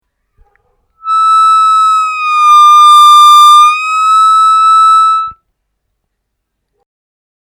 На 8-ом, 9-ом и 10-ом отверстиях понижать ноту до самого дна, возвращаясь затем к чистой ноте.